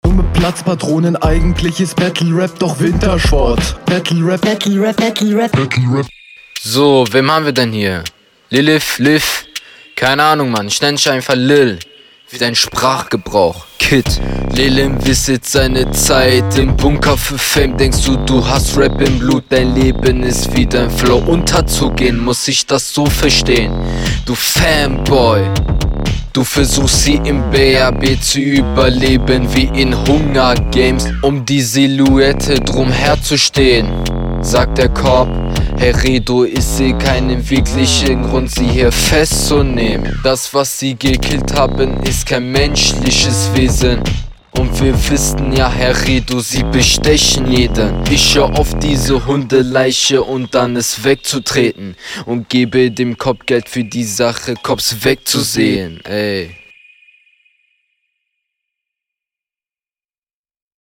Du flowst etwas unsicher, ich glaube der Beat lag dir …